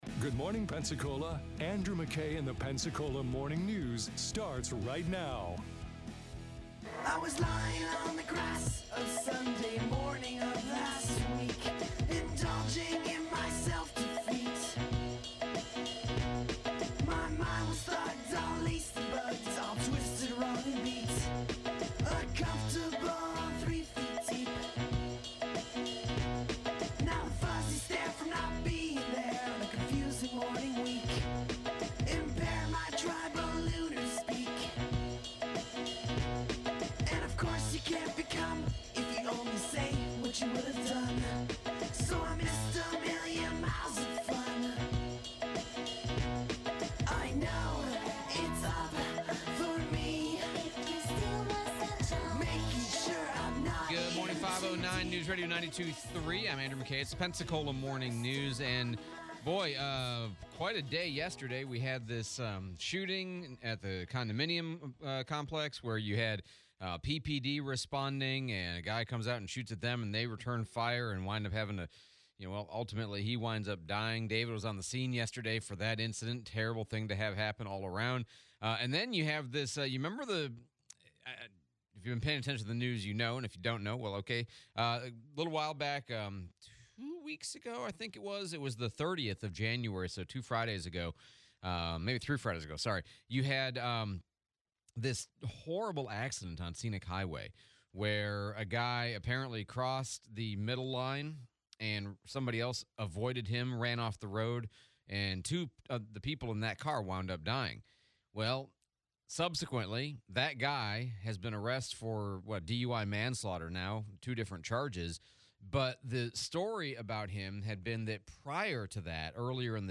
ECSO Body Cam audio, replay of US Congressman Jimmy Patronis